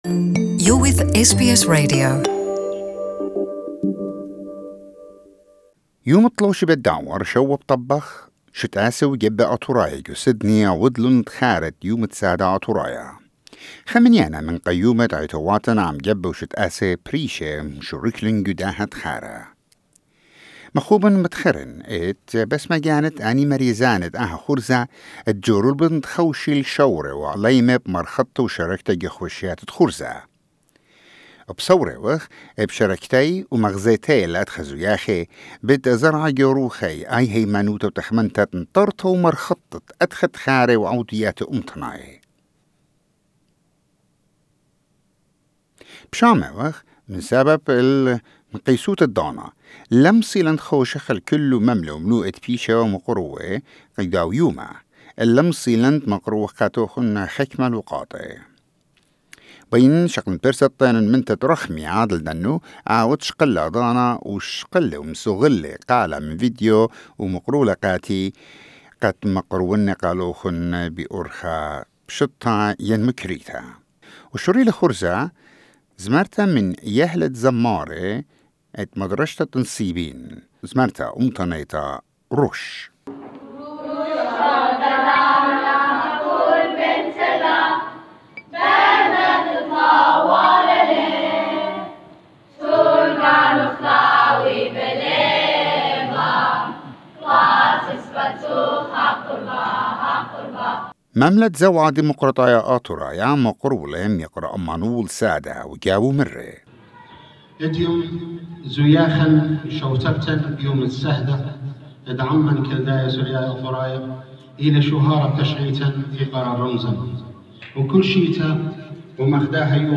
Highlights from commemoration Assyrian Martyrs Day
Assyrian political parties, churches and organisations in Sydney commemorated the Assyrian martyr'e day on 7th August at the Assyrian Cultural and sports Club. we bring you highlights of the program.